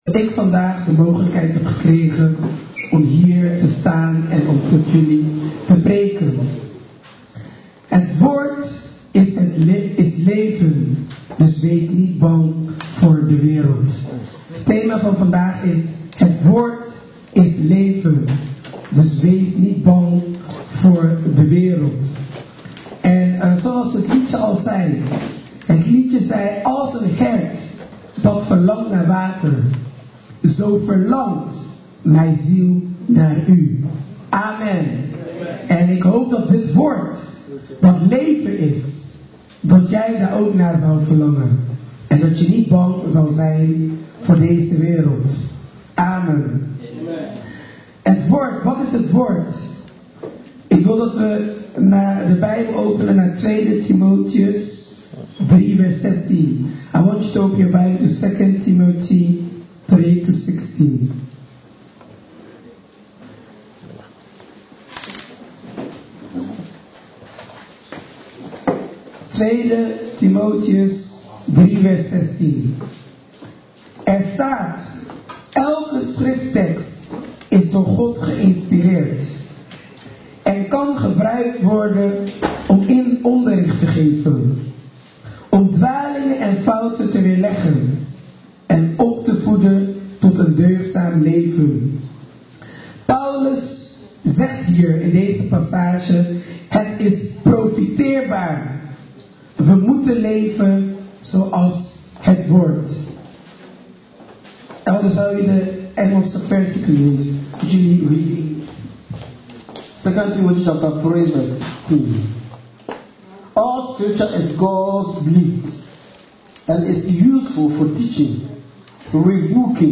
Dutch Assembly
sermon